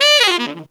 Index of /90_sSampleCDs/Best Service ProSamples vol.25 - Pop & Funk Brass [AKAI] 1CD/Partition C/TENOR FX2